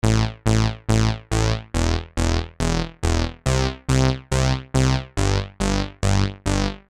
技术低音
描述：技术性的低音循环，速度为140bpm。